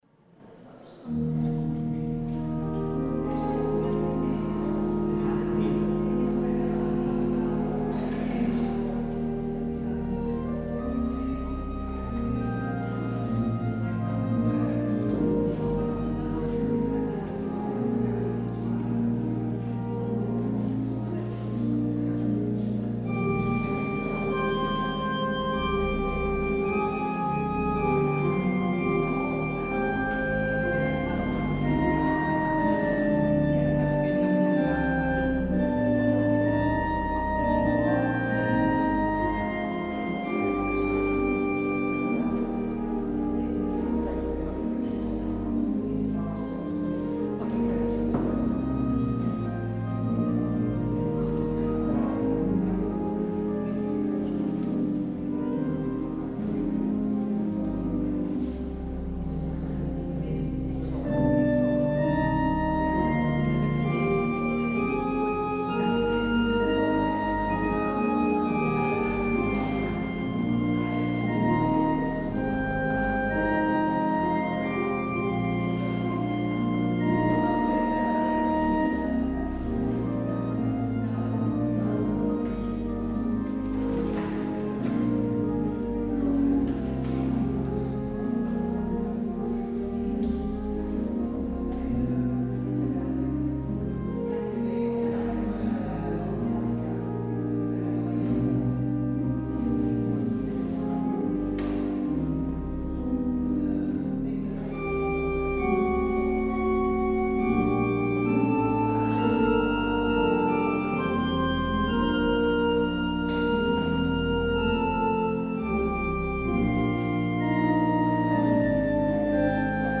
 Luister deze kerkdienst hier terug
Het openingslied is Psalm 26: vers 1 en 5. Als slotlied NLB 903: vers 1 en 6.